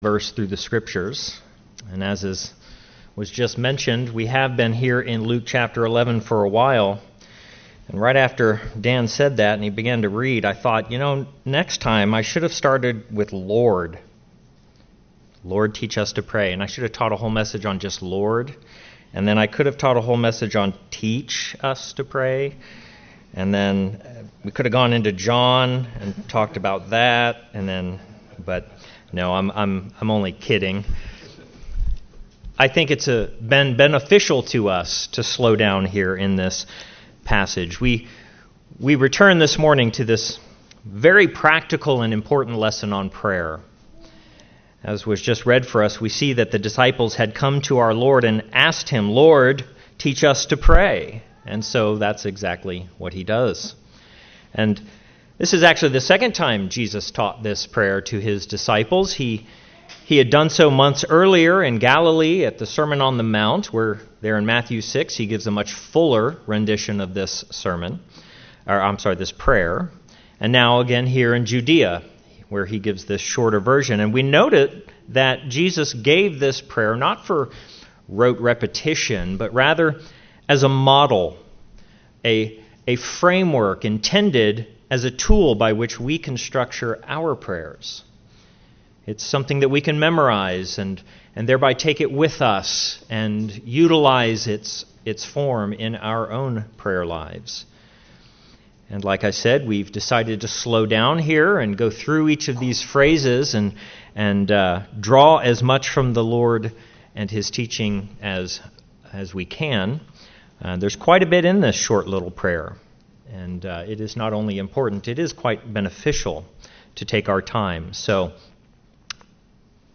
Grace Bible Chapel Non Denominational bible church verse-by-verse teaching